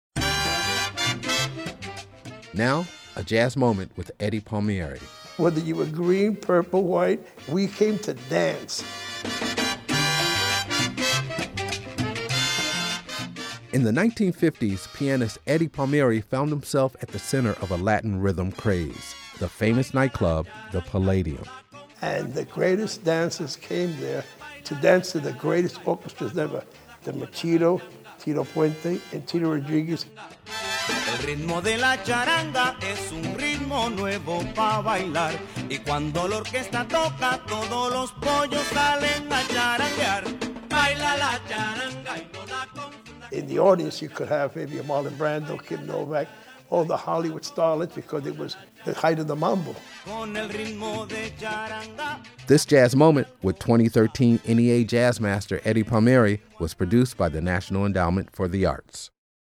"Baila la Charanga" up and under
Excerpt of "Baila la Charanga" composed by Tito Rodriguez and performed by Rodriguez with his Orchestra, used courtesy of FANIA music and by permission of Carlin America Inc. (BMI)